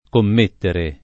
vai all'elenco alfabetico delle voci ingrandisci il carattere 100% rimpicciolisci il carattere stampa invia tramite posta elettronica codividi su Facebook commettere [ komm % ttere ] v.; commetto [ komm % tto ] — coniug. come mettere — cfr. mettere